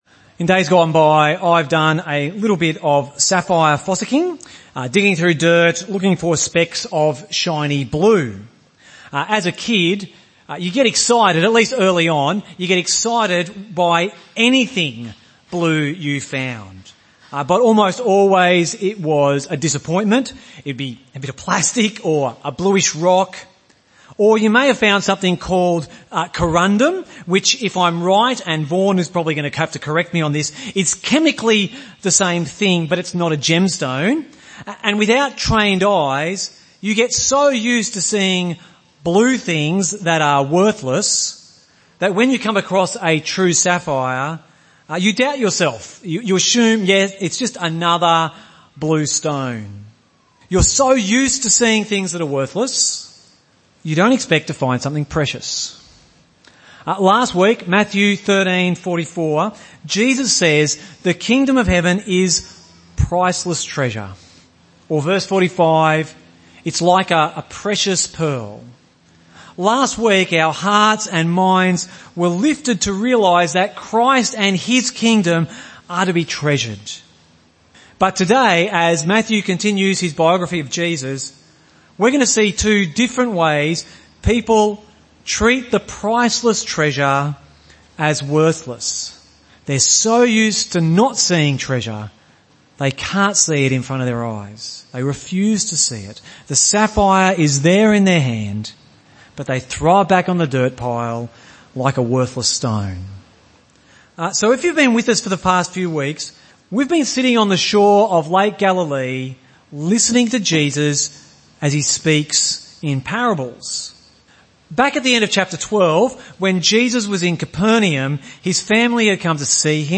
Weekly sermon podcast from Gympie Presbyterian Church